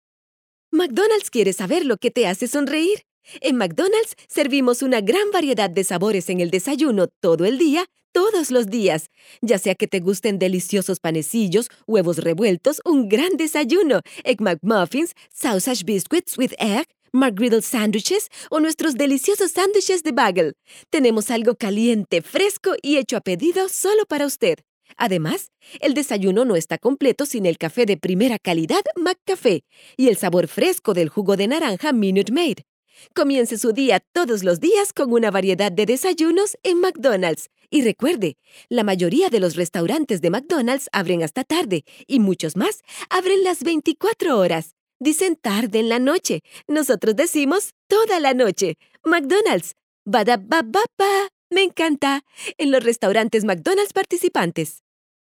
More than 25 years of experience recording commercials, e-learning, radio news, theatre, documentals, etc. in spanish latin american neutral.
I MAC with Pro Tools AKG 4000 microphone Pre amp Tube Bellari, DBX Compressor, Module Aphex
Sprechprobe: Sonstiges (Muttersprache):